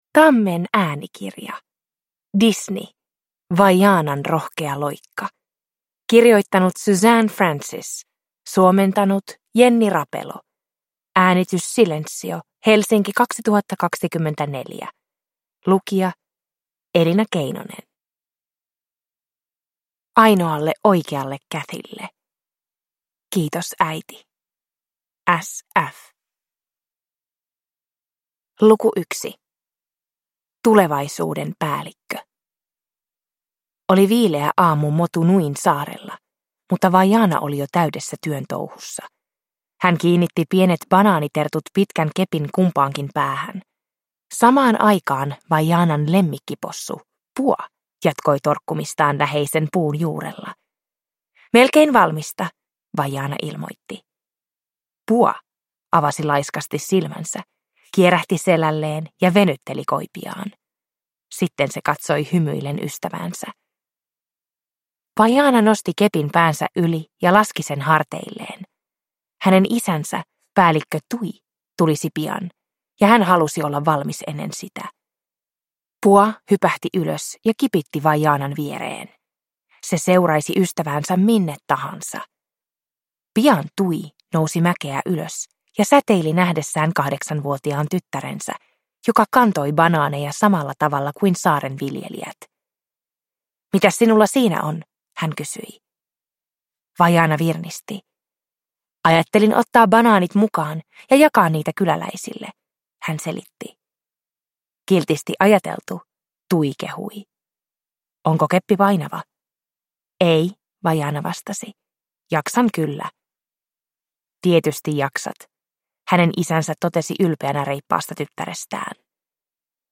Disney. Prinsessat. Vaianan rohkea loikka – Ljudbok